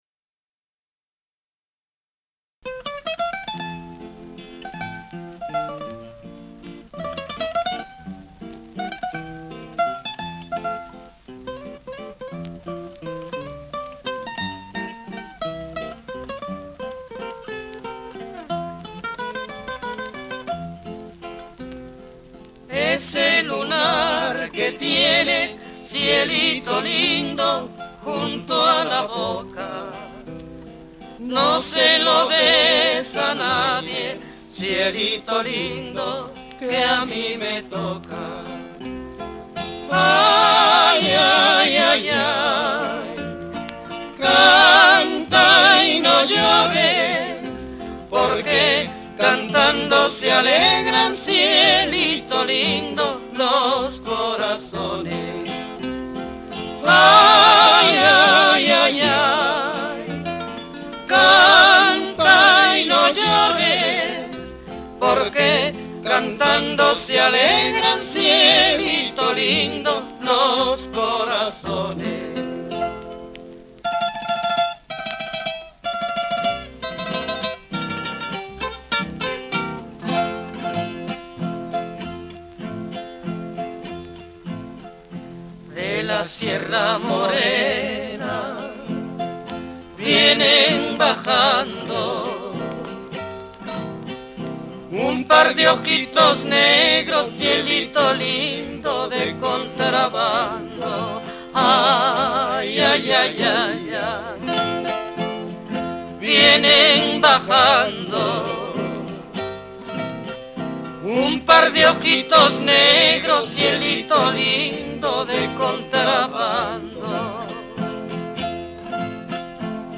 High Fidelity recording